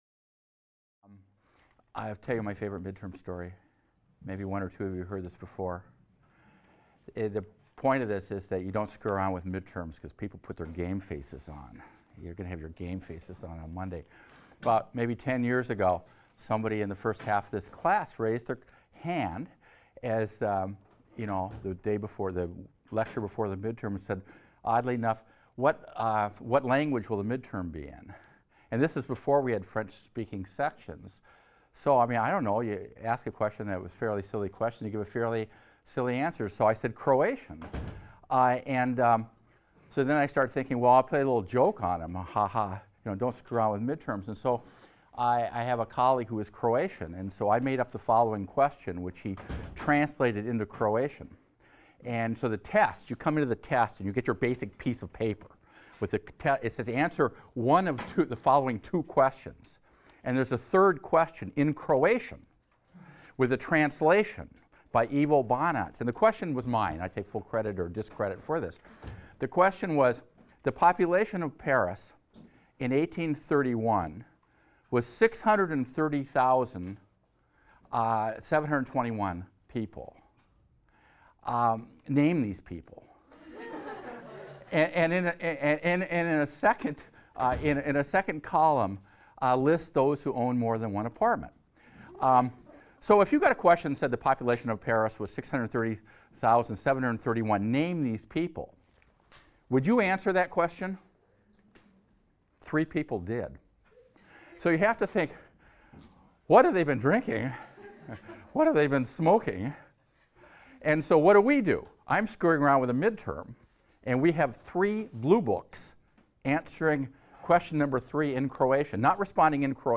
HIST 276 - Lecture 15 - The Home Front | Open Yale Courses